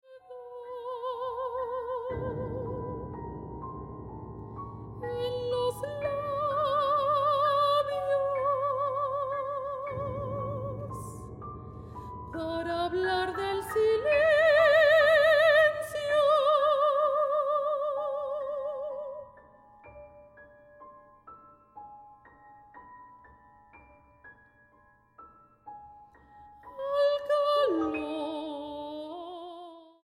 para contralto, piano, armonio y celesta.
mezzosoprano